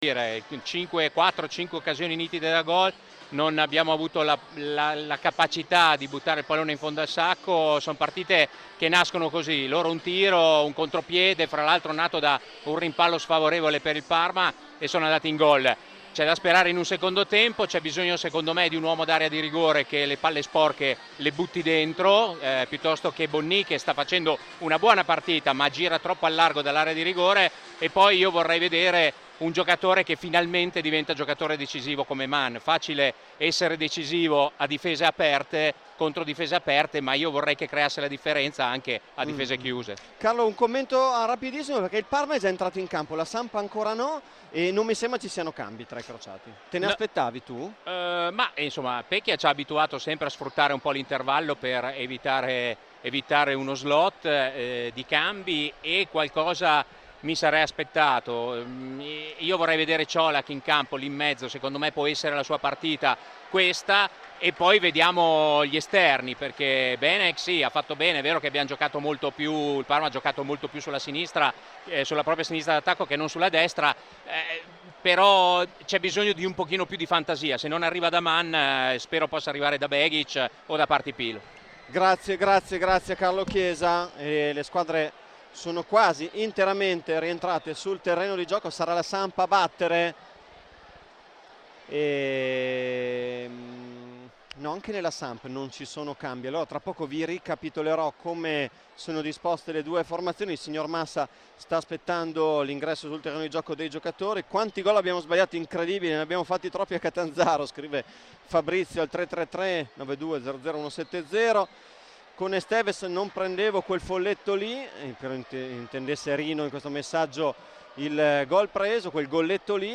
Radiocronaca